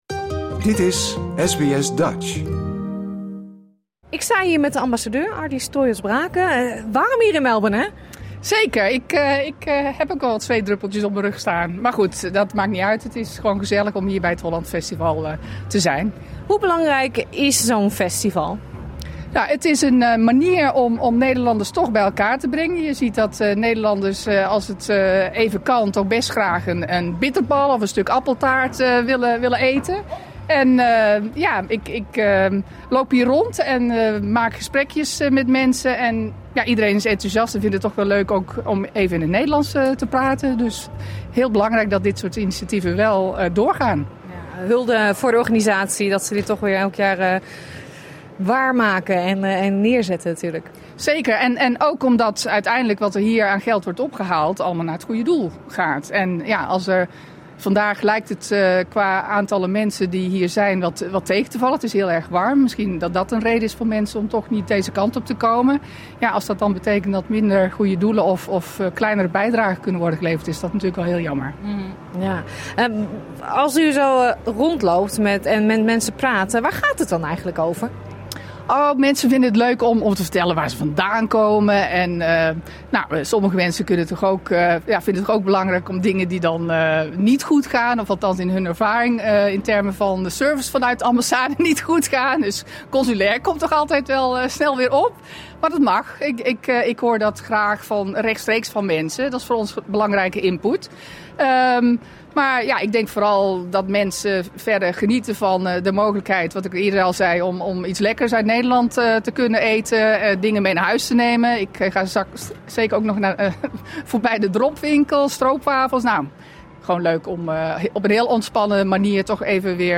De Nederlandse ambassadeur in Australië Ardi Stroios-Braken was afgelopen weekend aanwezig op het Holland Festival in Caribbean Gardens. Wij vroegen haar naar het belang van een festival waarin de Nederlandse cultuur centraal staat.